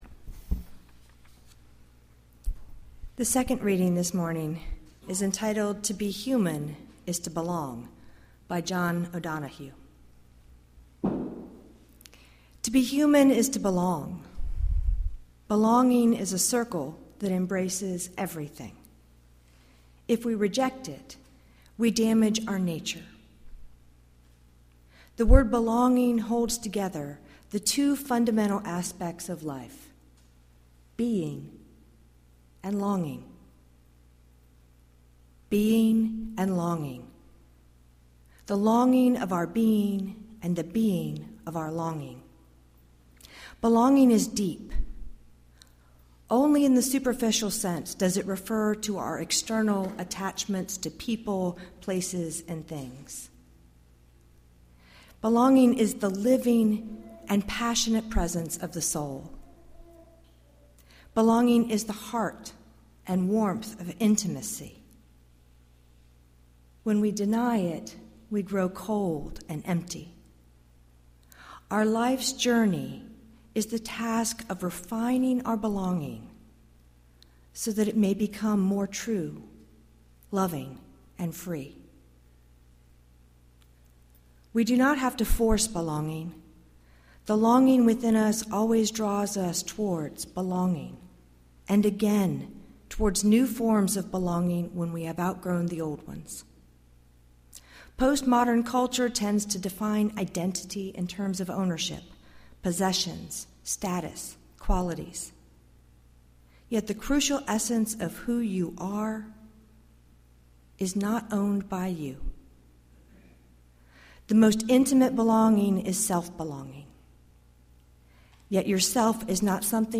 Bring your touchstone, your summer water, for our Ingathering Ceremony to gather this Fellowship in affirmation of our shared humanity for another journey around the sun.